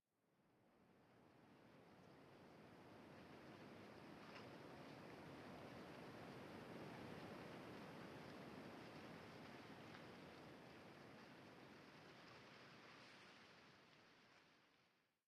Minecraft Version Minecraft Version latest Latest Release | Latest Snapshot latest / assets / minecraft / sounds / ambient / nether / crimson_forest / particles3.ogg Compare With Compare With Latest Release | Latest Snapshot
particles3.ogg